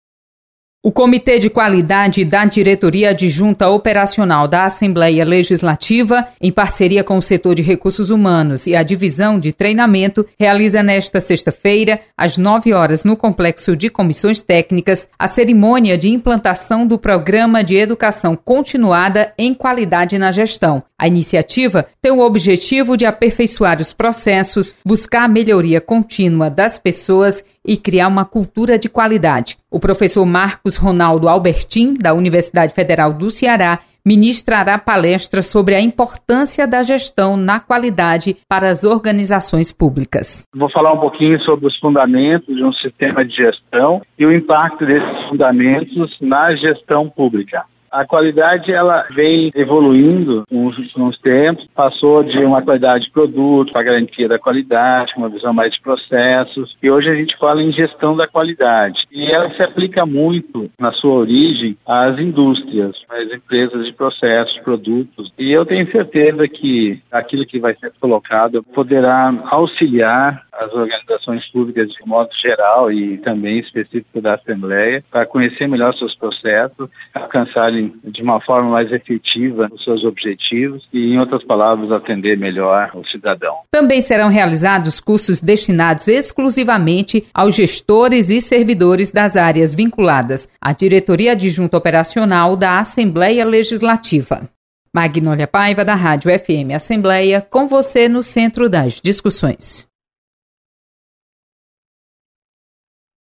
Assembleia implanta programa de Educação Continuada em Qualidade. Repórter